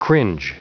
Prononciation du mot cringe en anglais (fichier audio)
Prononciation du mot : cringe